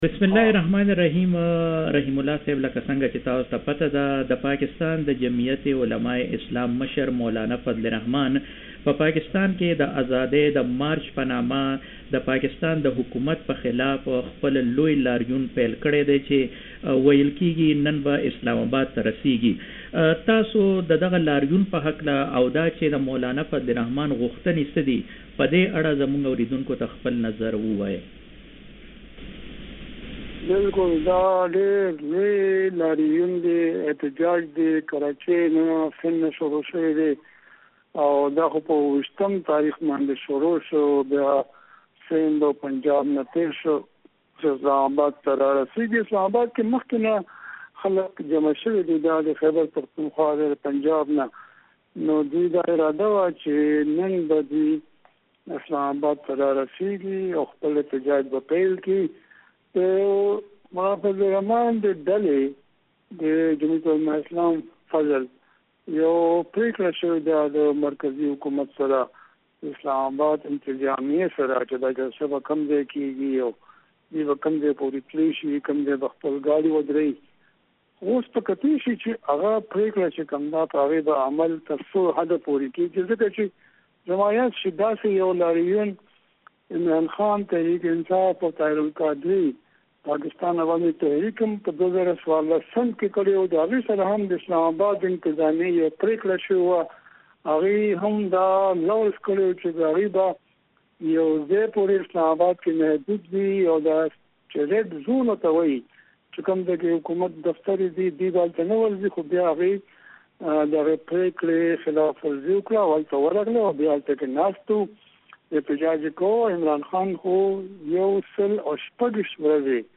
د تکړه خبریال ښاغلی رحیم الله یوسفزی مرکه دلته واورئ